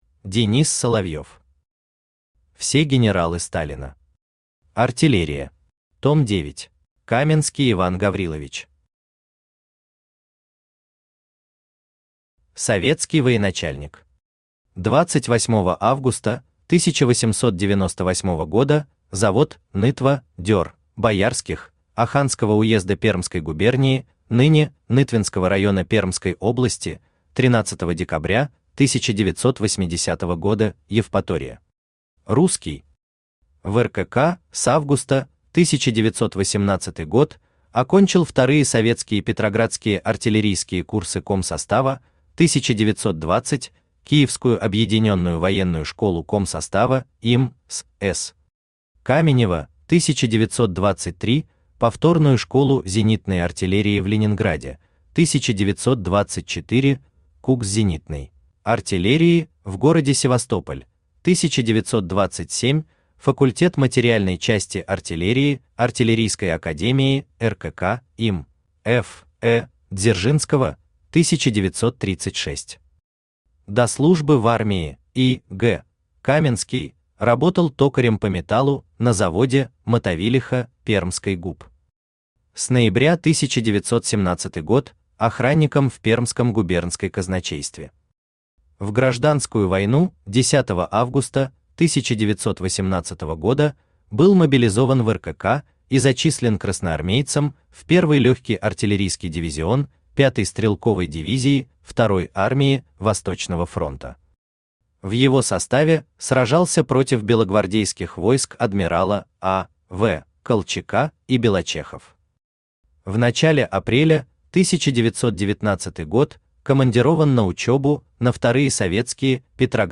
Аудиокнига Все генералы Сталина. Артиллерия. Том 9 | Библиотека аудиокниг
Том 9 Автор Денис Соловьев Читает аудиокнигу Авточтец ЛитРес.